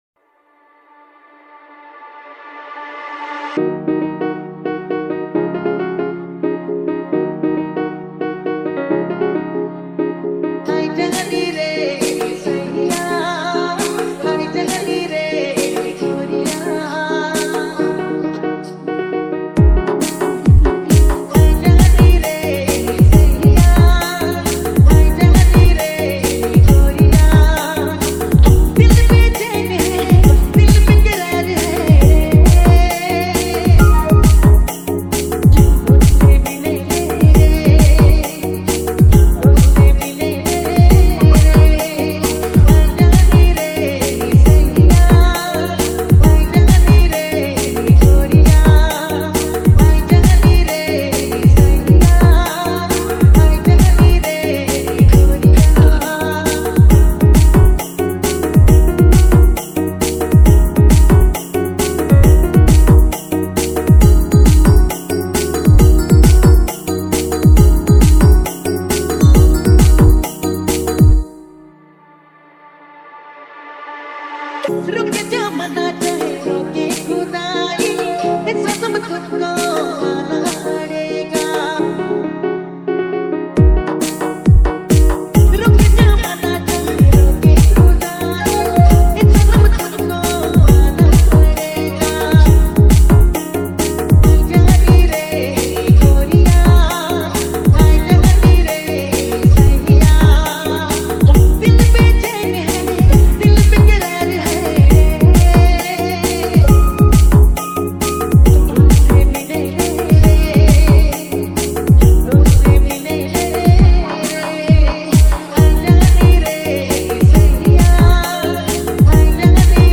NAGPURI DJ REMIX